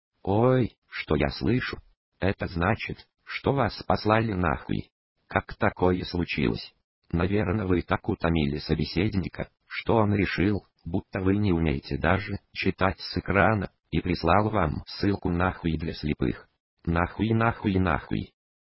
сообщение о том что вас послали на* и почиму так случилось эстонский акцент = )